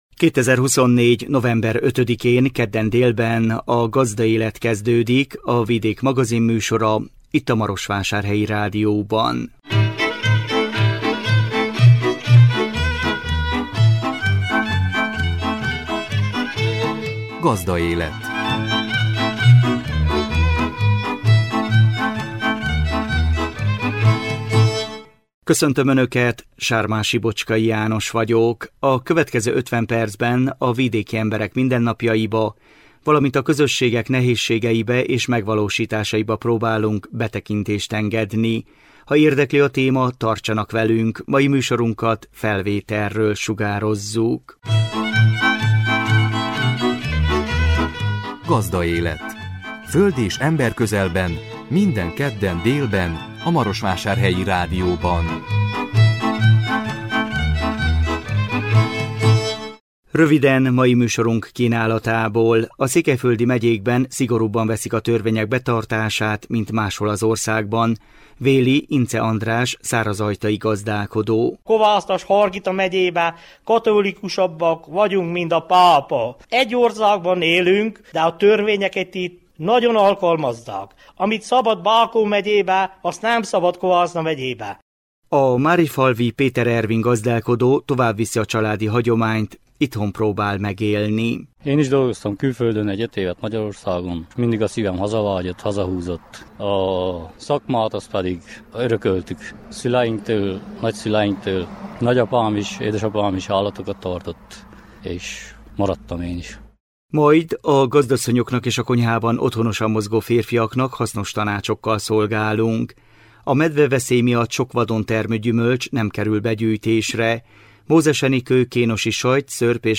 Nála jártunk mikrofonunkkal.